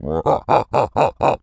Animal_Impersonations
lizard_taunt_emote_02.wav